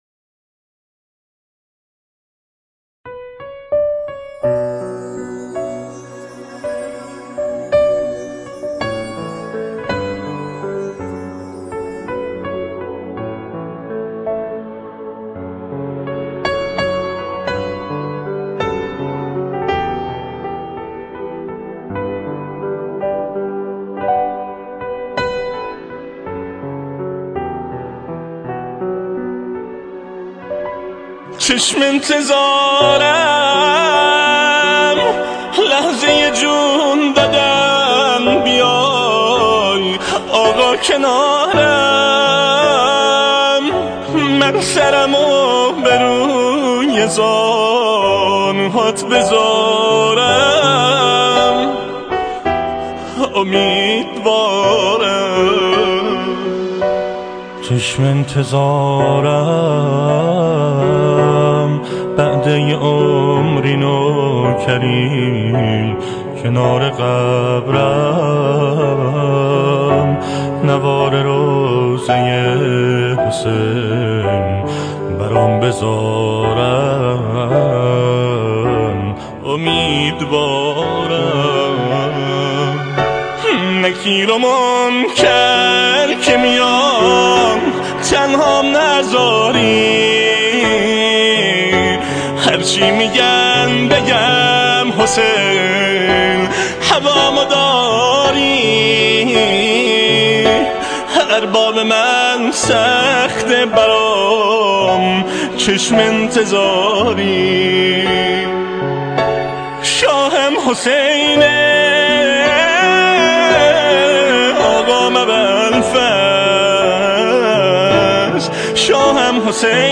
آهنگ شب ششم محرم